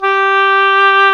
WND OBOE2 04.wav